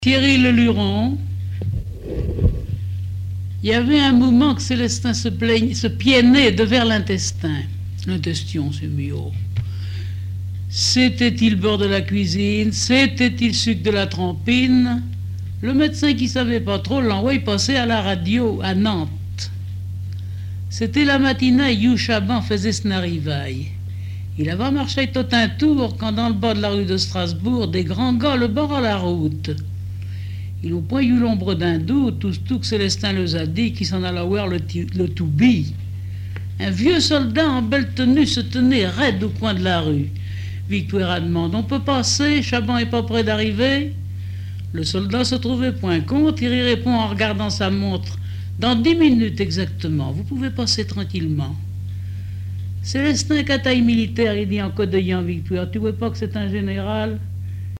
Langue Patois local
Genre récit
textes en patois et explications sur la prononciation